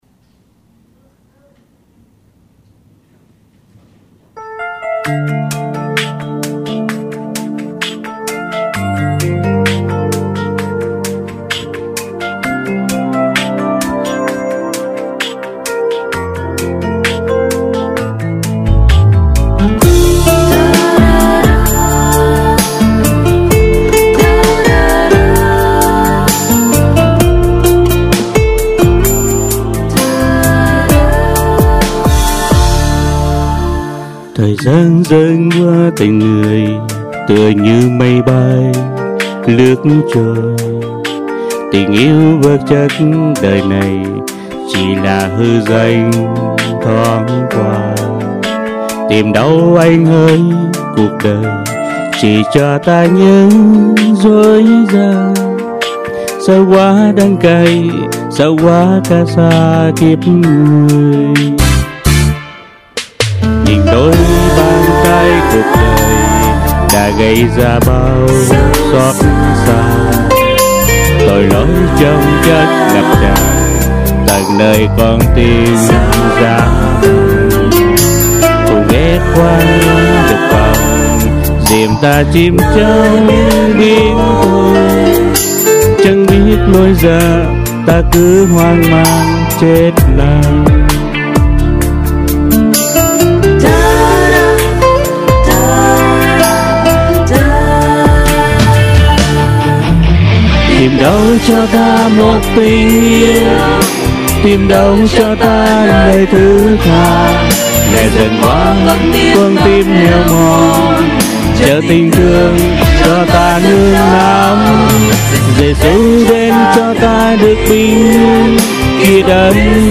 Music/Nhạc